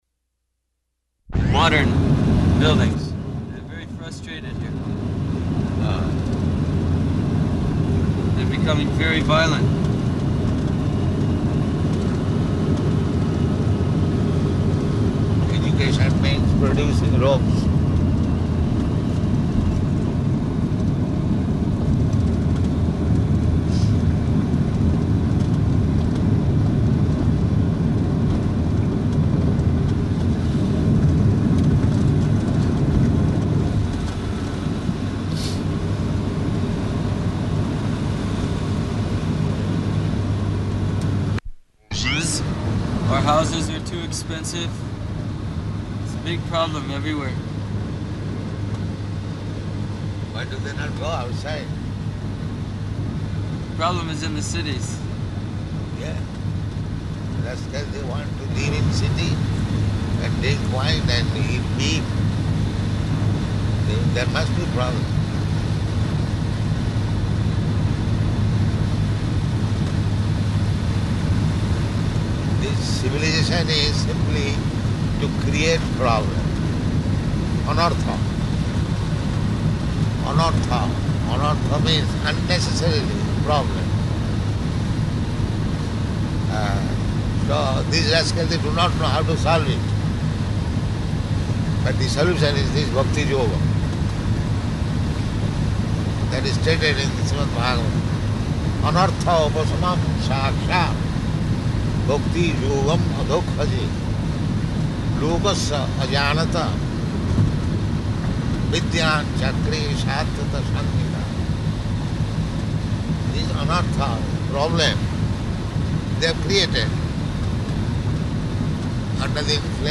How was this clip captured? -- Type: Conversation Dated: June 12th 1974 Location: Paris Audio file